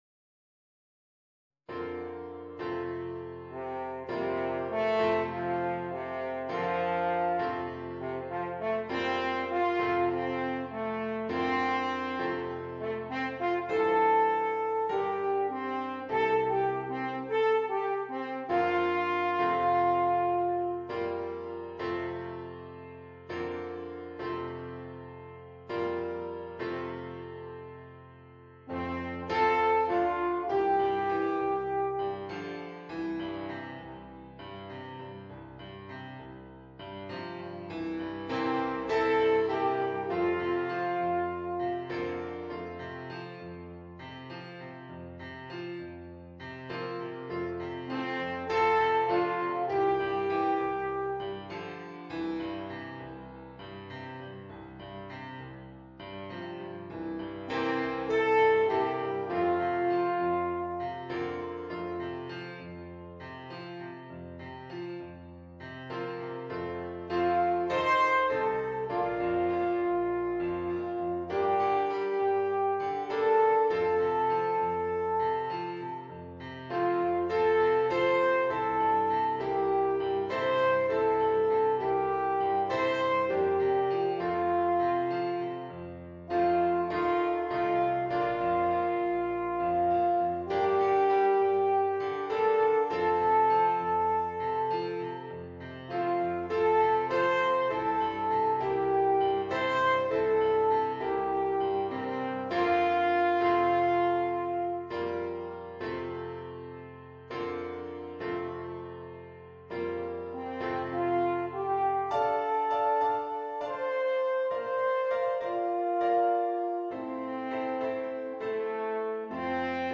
Voicing: Alphorn w/ Audio